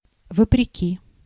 Ääntäminen
Synonyymit несмотря вразрез Ääntäminen Tuntematon aksentti: IPA: /vəprʲɪˈkʲi/ Haettu sana löytyi näillä lähdekielillä: venäjä Käännös Ääninäyte 1. niettegenstaande 2. ondanks Translitterointi: vopreki.